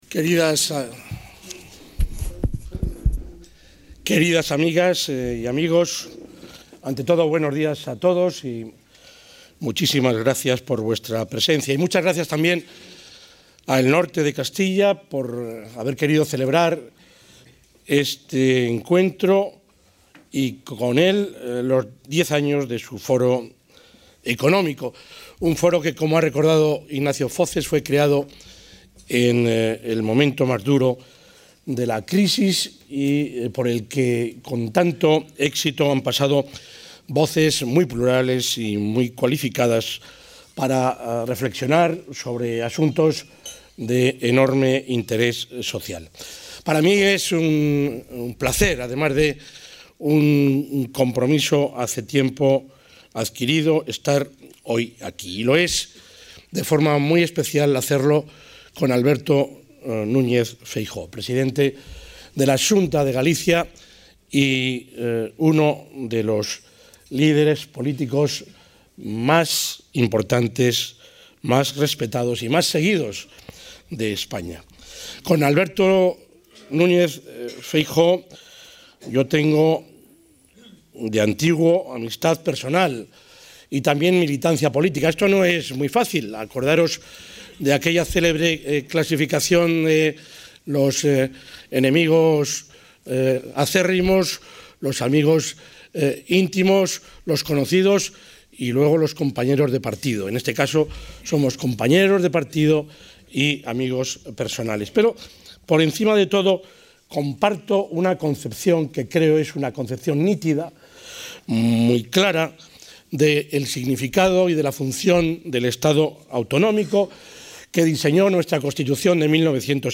El presidente de la Junta de Castilla y León, Juan Vicente Herrera, ha participado hoy en Valladolid, junto al presidente de la Xunta de Galicia, Alberto Núñez Feijóo, en el Foro sobre los ‘Desafíos socieconómicos de las regiones atlánticas’, organizado por El Norte de Castilla. Entre dichos desafíos, Herrera ha situado la demografía, la financiación autonómica, las infraestructuras y la industria.